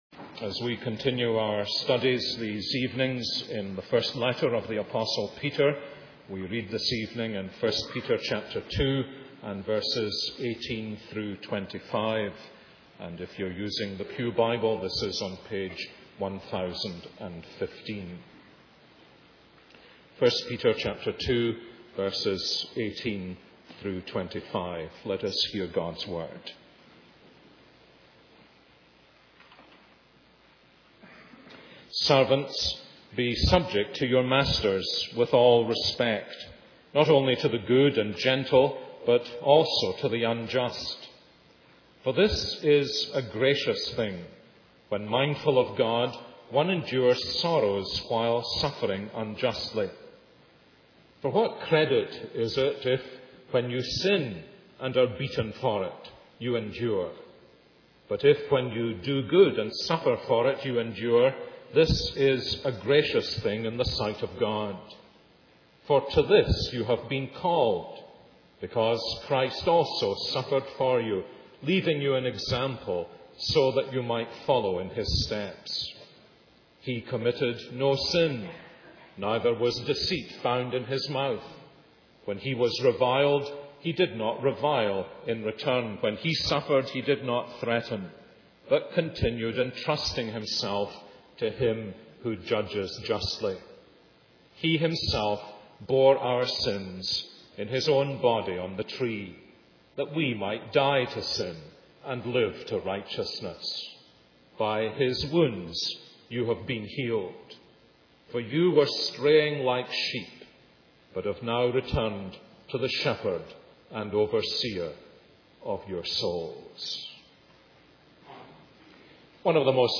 This is a sermon on 1 Peter 1:18-25.